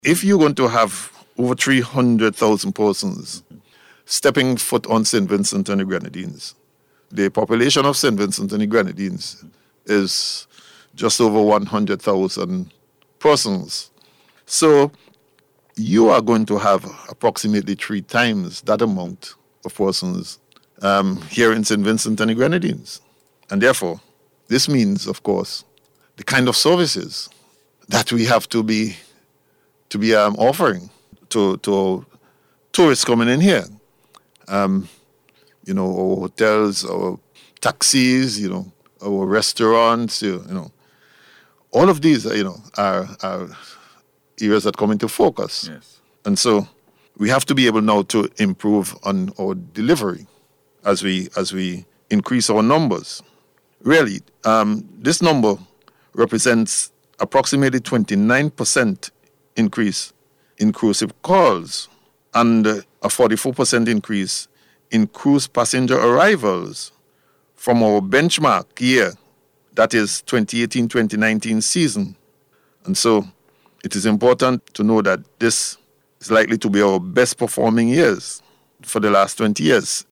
Speaking on NBC’s Face to Face Program yesterday, Acting Prime Minister, Montgomery Daniel said the Tourism Sector is expected to see a major boost with the influx of visitors expected here during the Cruise Season.